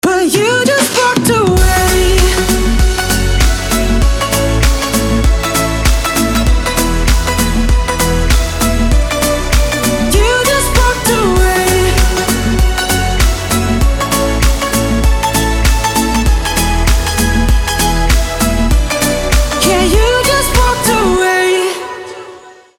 • Качество: 320, Stereo
красивые
dance